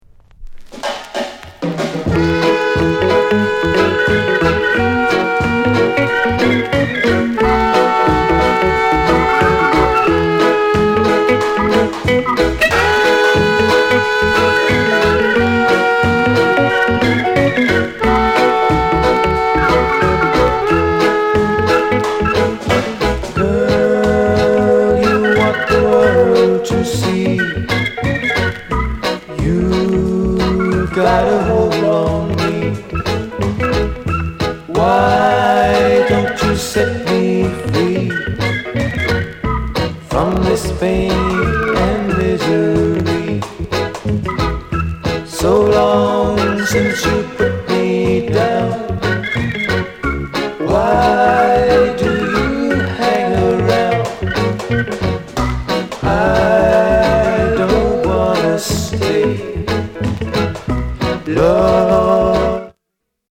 RARE ROCKSTEADY